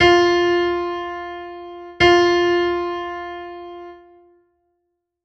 Para ir quentando a gorxa, faremos unha ronda de quecemento coas notas da escala musical, que poderedes empregar como referencia tonal para non perder a voz unha vez comece o xogo! NOTA DO audio/wav NOTA RE audio/wav NOTA MI audio/wav NOTA FA audio/wav NOTA SOL audio/wav NOTA LA audio/wav NOTA SI audio/wav
FA.wav